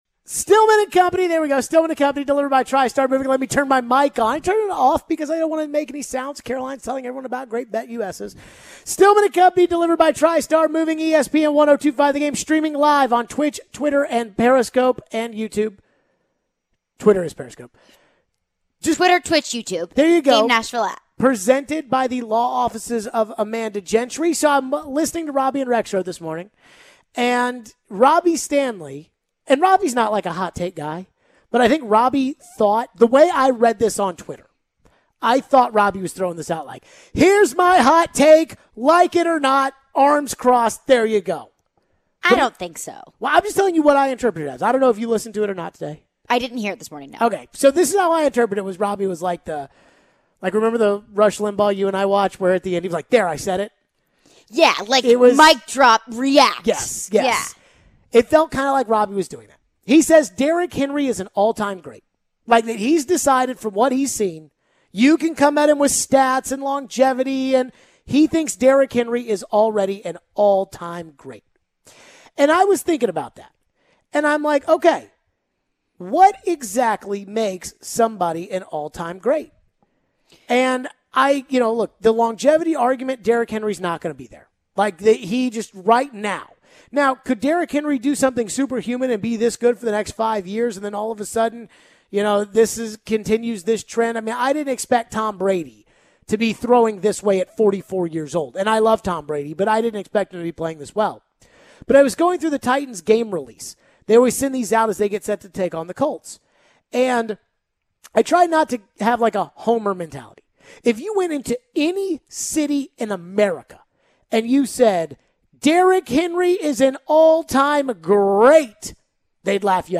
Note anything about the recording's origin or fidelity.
We take your calls and texts on Henry. We finish up the show with 'CaroLINES'.